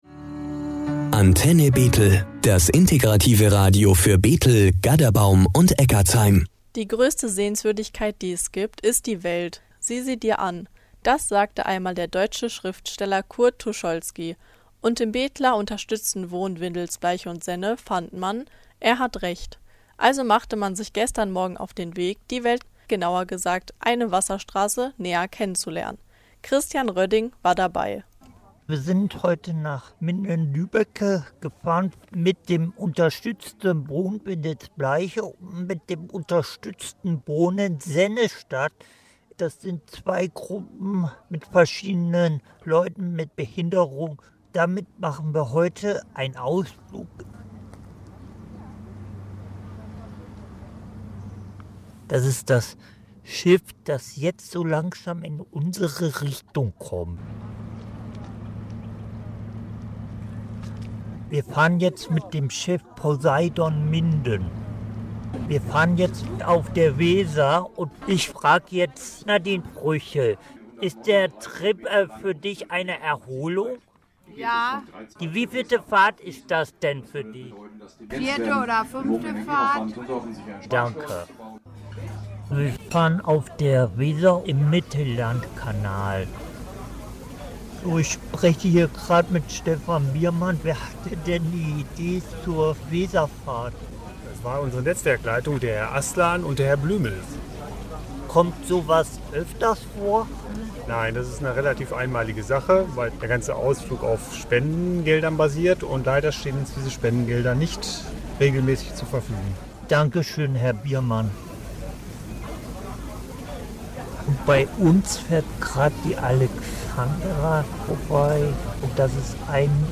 Reportage-Schiffsausflug-unterstuetztes-Wohnen-Bethel.mp3